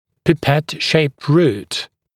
[pɪ’pet ʃeɪpt ruːt][пи’пэт шэйпт ру:т]пипеткообразный корень